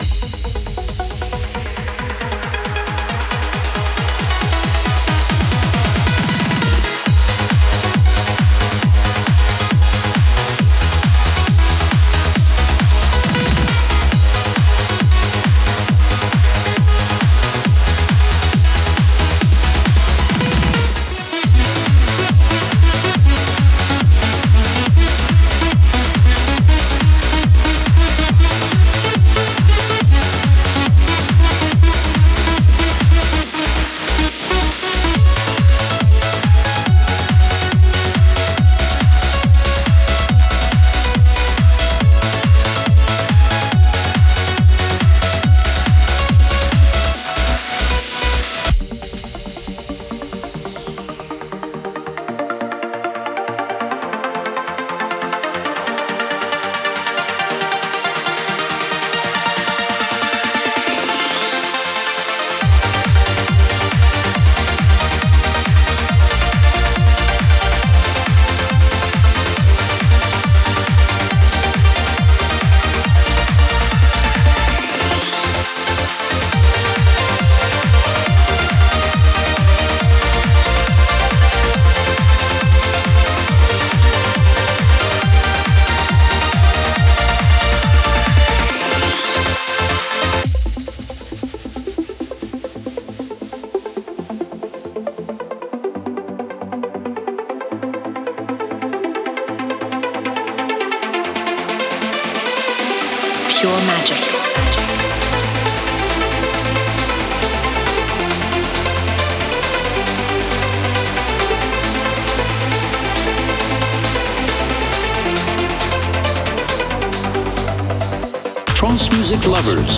Type: Spire Midi Samples